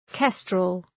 {‘kestrəl}